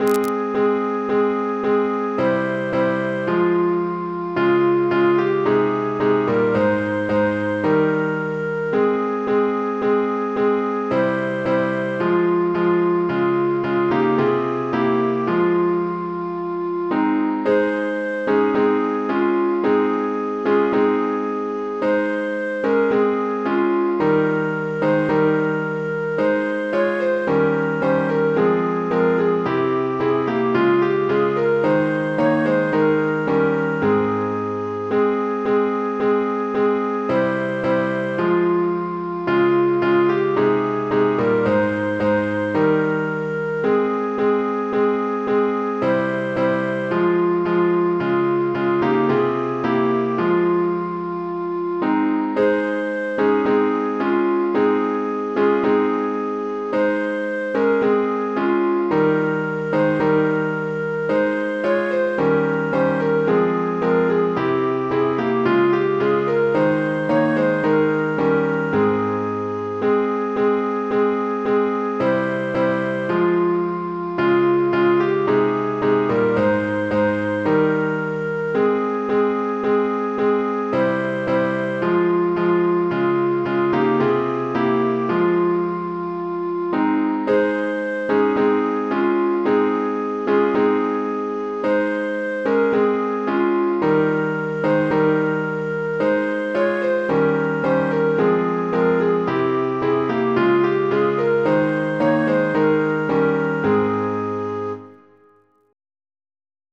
piano, keyboard, keys
Мелодия за разучаване и файл за изтегляне: